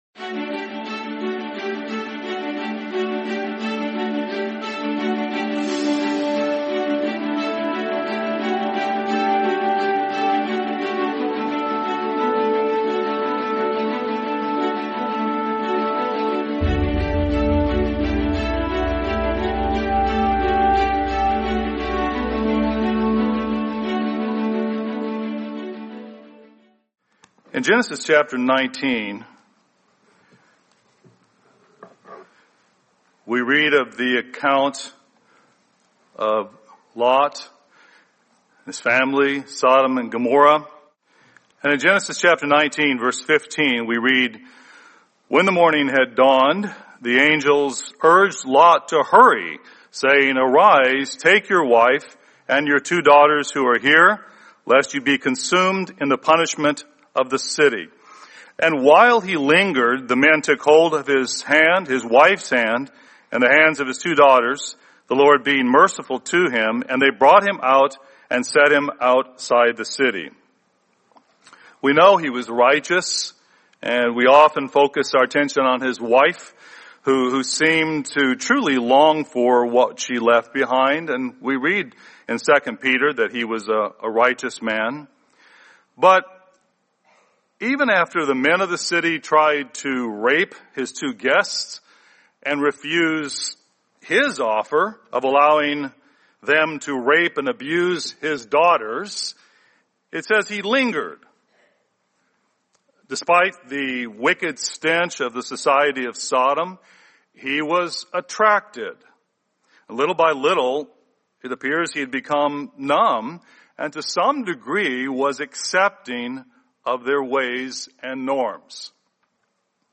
Sermon Gambling